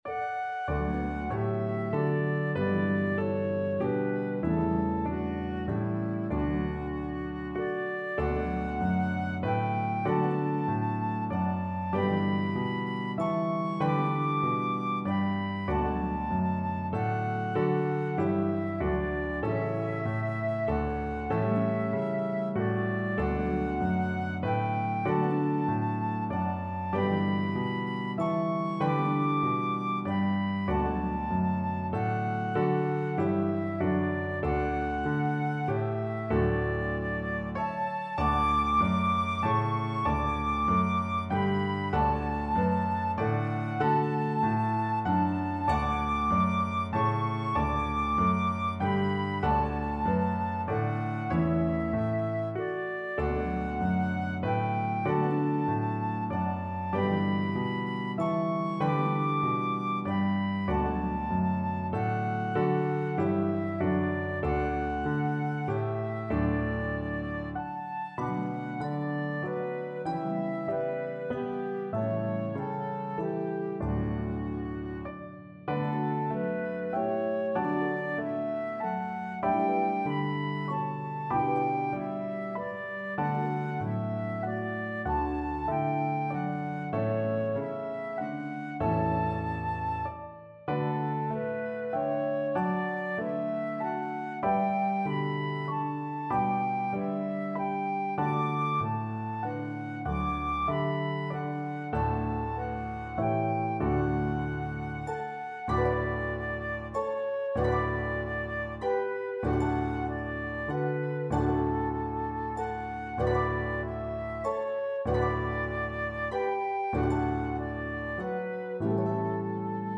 Harp, Piano, and Flute version